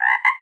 animalia_frog_3.ogg